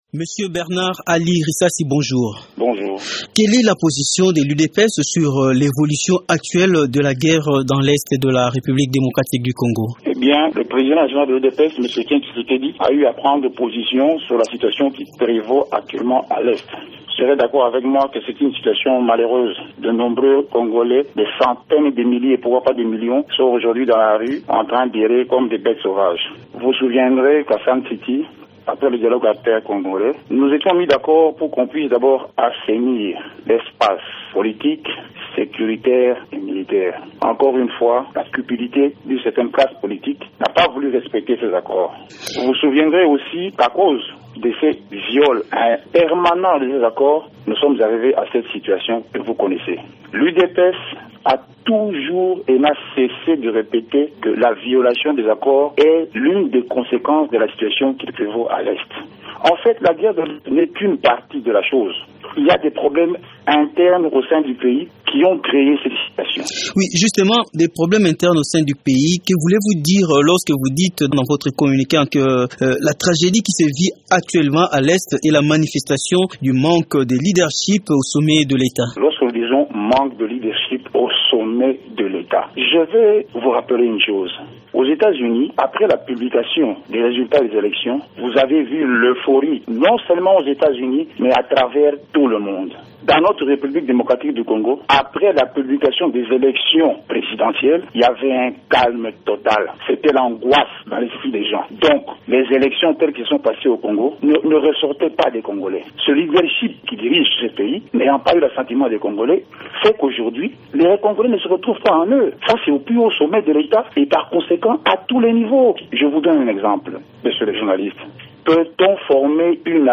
s’entretient à ce sujet avec